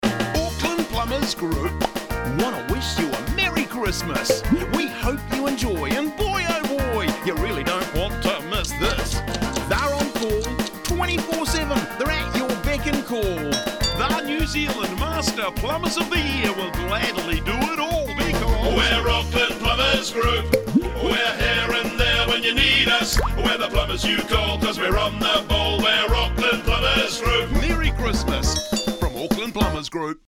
Celebrate the holidays with our festive jingle!